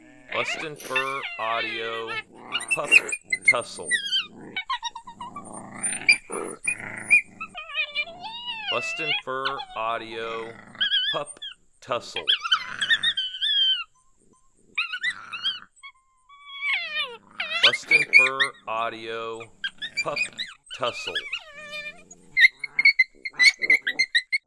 Coyote pup aggravating another pup with growls and aggression causing the intimidated pup to squeal.